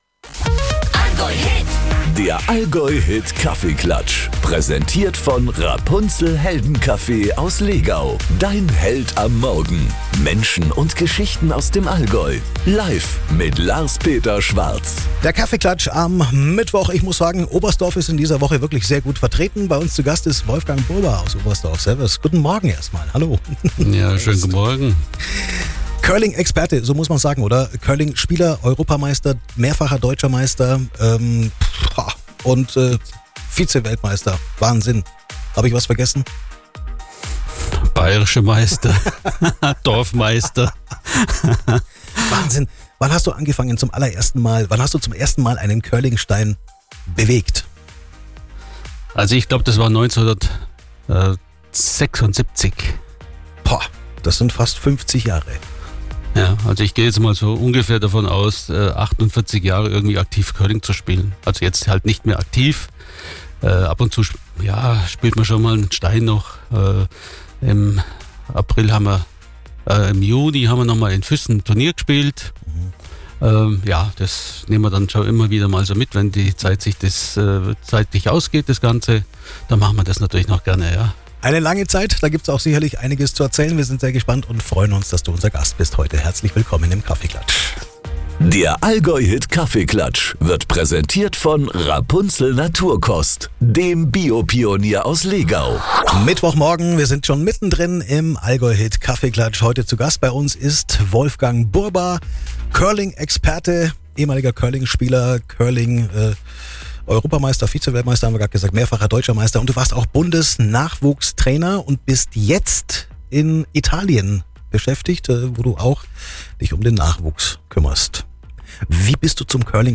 Heute hat er uns im AllgäuHIT Kaffeeklatsch besucht.